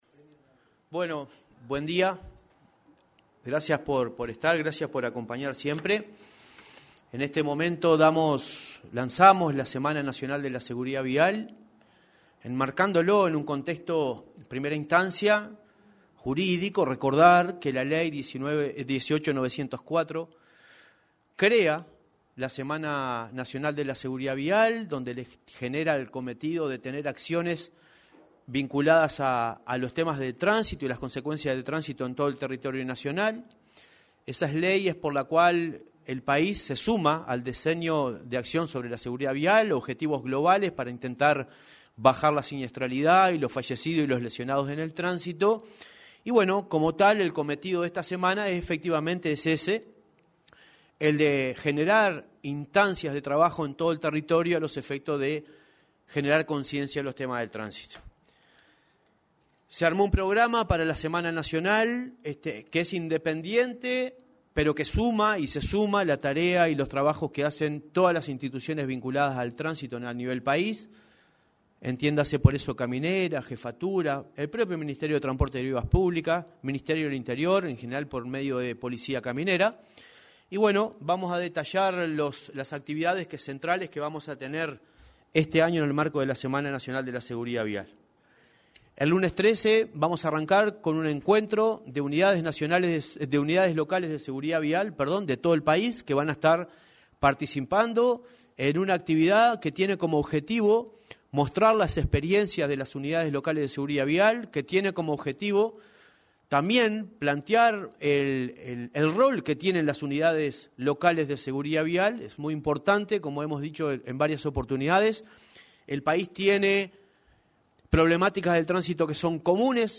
El presidente de la Unidad Nacional de Seguridad Vial (Unasev), Marcelo Metediera, informó, en una conferencia de prensa, acerca de la Semana Nacional
Declaraciones del presidente de la Unasev, Marcelo Metediera